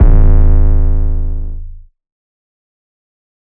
808 TRP23_990.wav